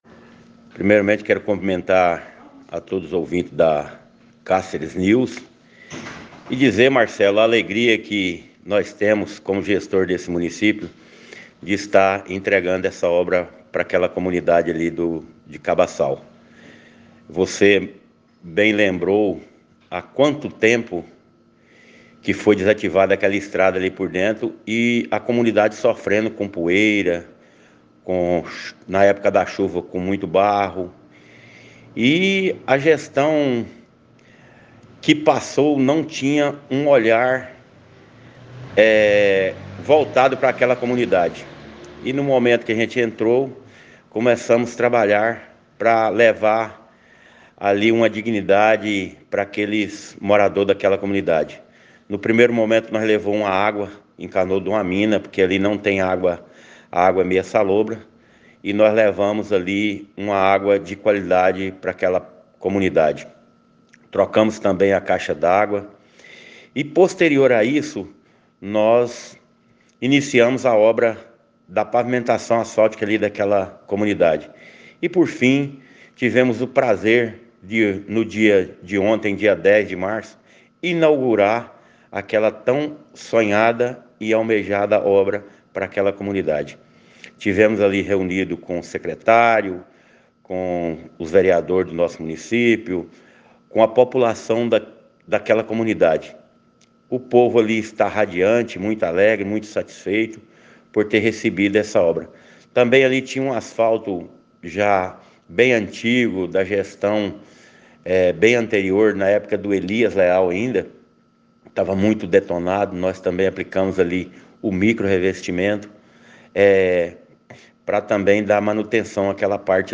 O prefeito do município de Curvelândia, Jadilson Alves (Republicanos) entregou a pavimentação asfáltica da via da Vila Cabaçal com a rodovia MT-170. O prefeito cedeu uma entrevista ao Cáceres News, ouça abaixo.